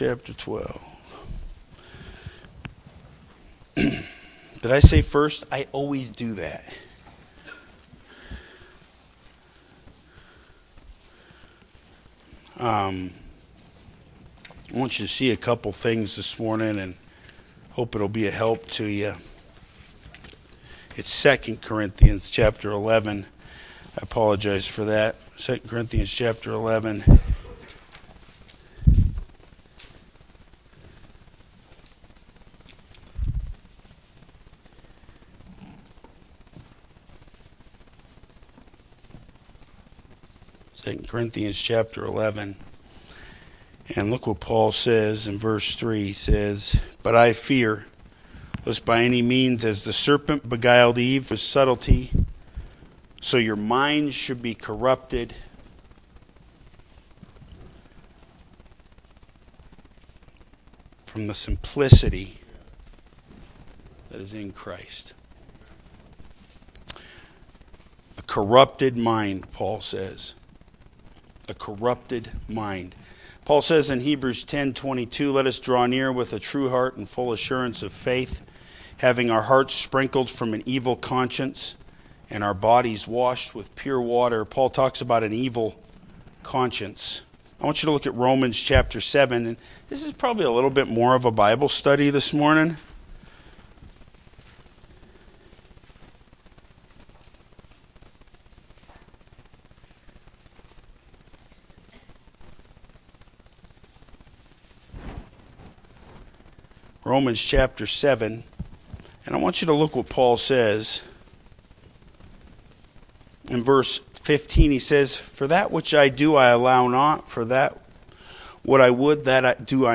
Main Service